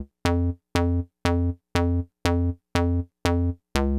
TSNRG2 Off Bass 006.wav